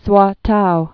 (swätou)